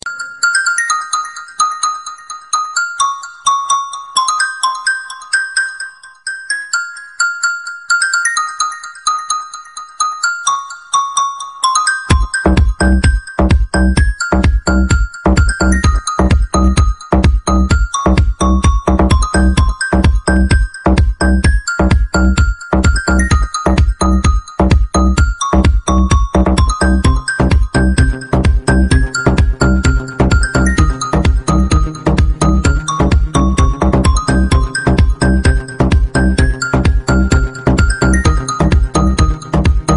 Cute message sound ringtone free download
Message Tones